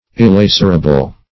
illacerable - definition of illacerable - synonyms, pronunciation, spelling from Free Dictionary
Search Result for " illacerable" : The Collaborative International Dictionary of English v.0.48: Illacerable \Il*lac"er*a*ble\, a. [L. illacerabilis: cf. F. illac['e]rable.